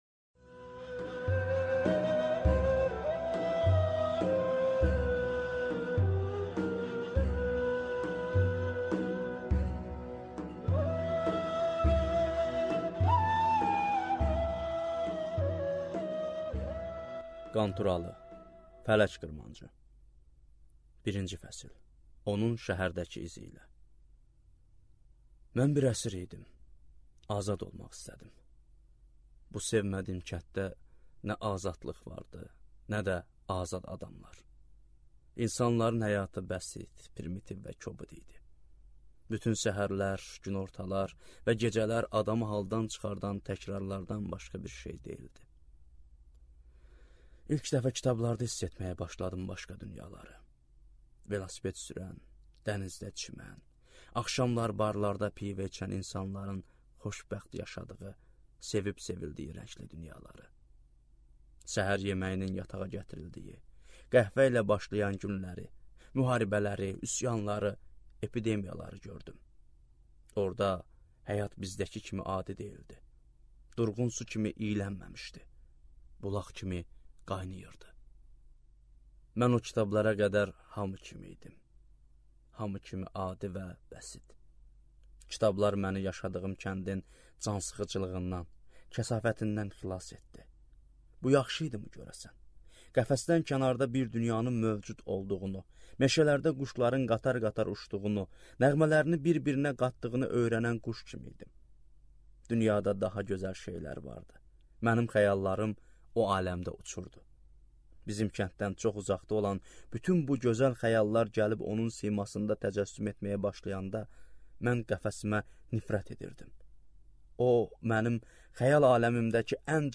Аудиокнига Fələk qırmancı | Библиотека аудиокниг